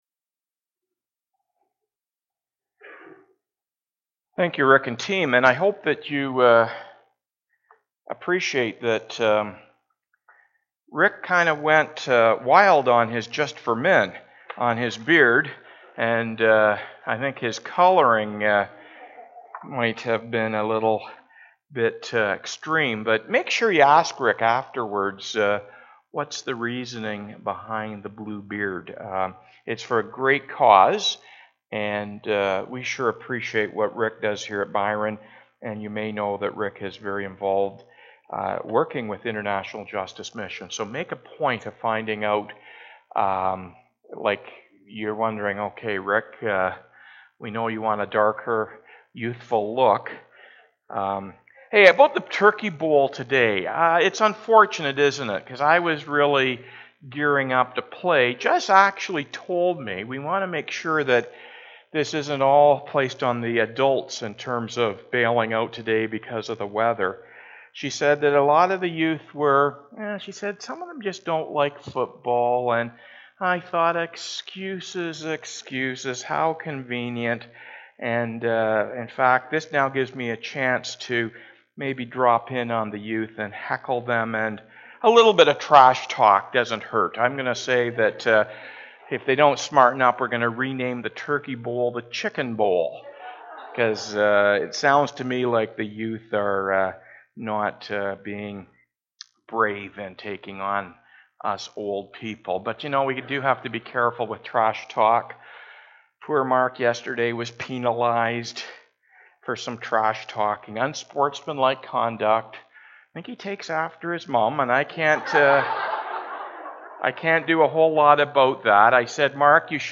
Weekly Sermons - Byron Community Church